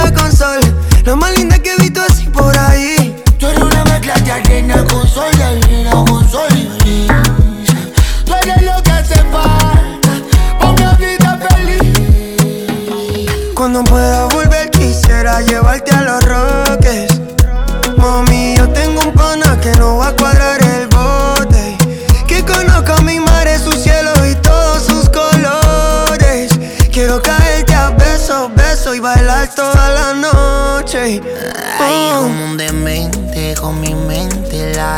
# Pop Latino